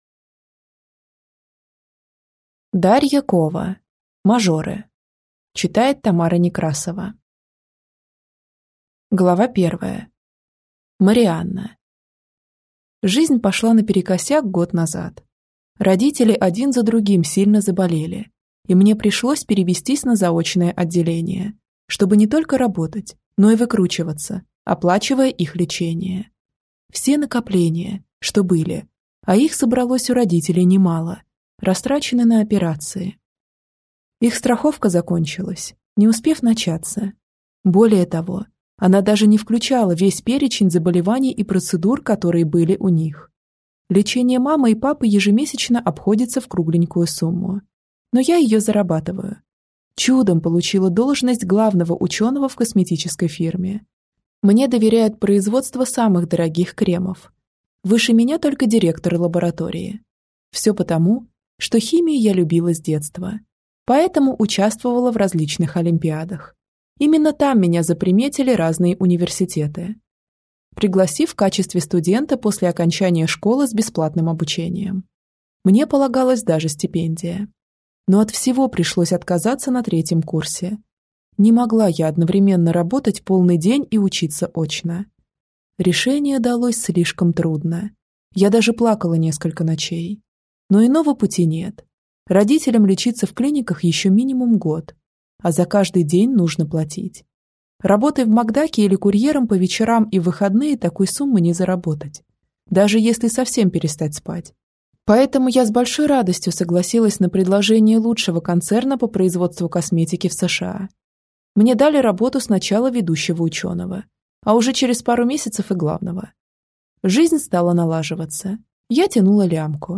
Аудиокнига Мажоры | Библиотека аудиокниг